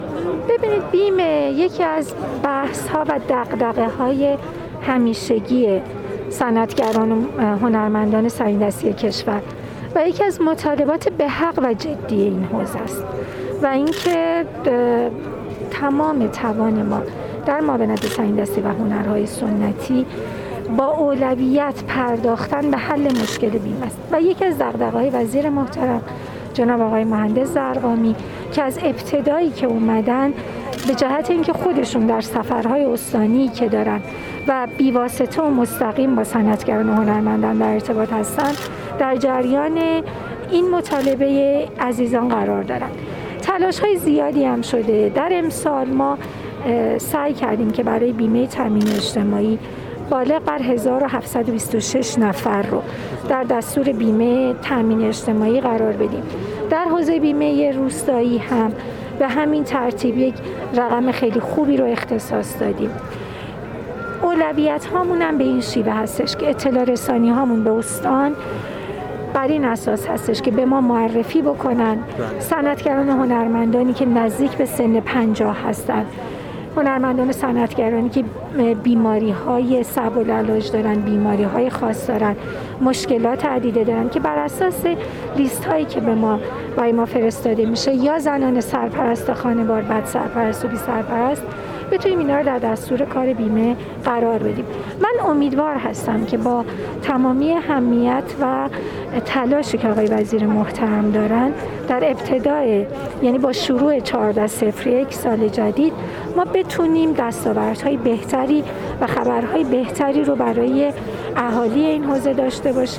در گفت‌وگوی پیش‌رو با پویا محمودیان، معاون صنایع‌دستی و هنرهای سنتی وزارت میراث فرهنگی، گردشگری و صنایع‌دستی در مورد مطالبات صنعتگران و هنرمندان و همچنین چالش‌ها و فرصت‌های ادغام دو نمایشگاه گردشگری و صنایع‌دستی صحبت کردیم.